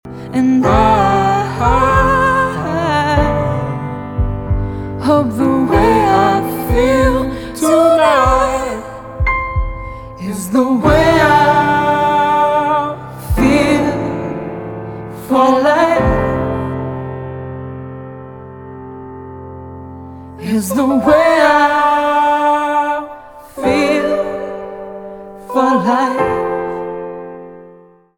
инди
красивые , чувственные , спокойные , пианино